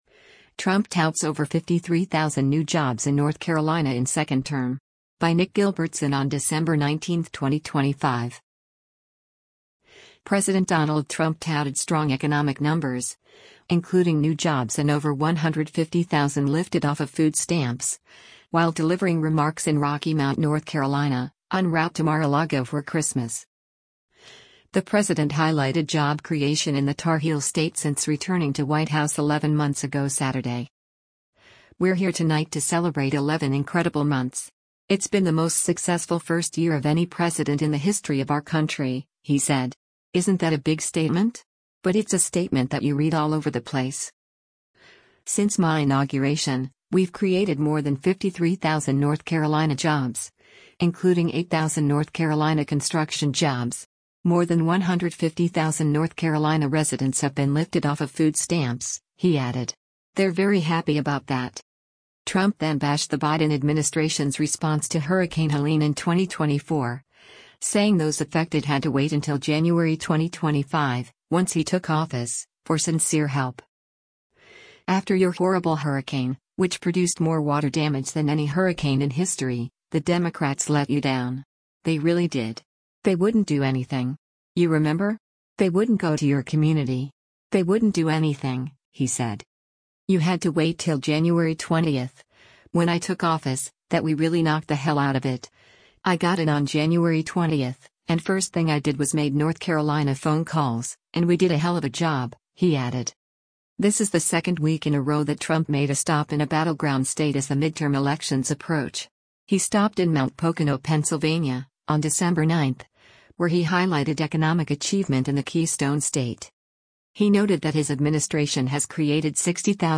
U.S. President Donald Trump takes the stage during a rally at the Rocky Mount Event Center
President Donald Trump touted strong economic numbers, including new jobs and over 150,000 lifted off of food stamps, while delivering remarks in Rocky Mount, North Carolina, en route to Mar-a-Lago for Christmas.